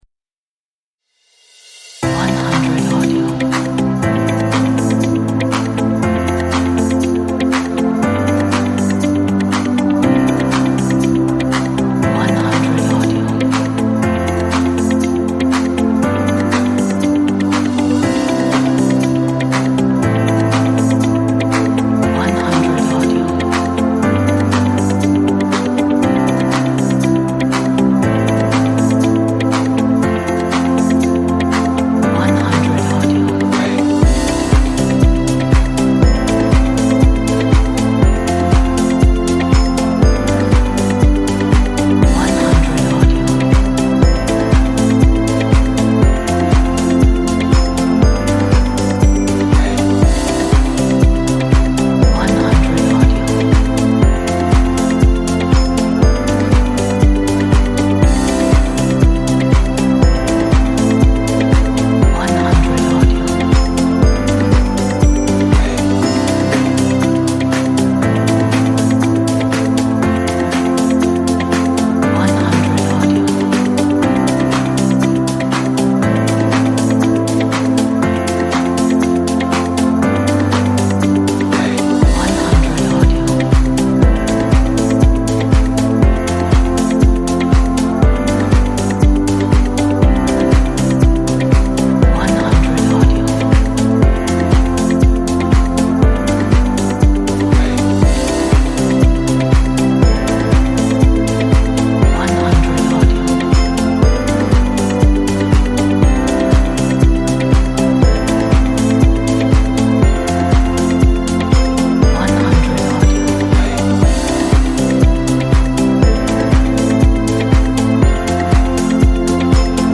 Very inspiring!
Motivated motivational upbeat power energy.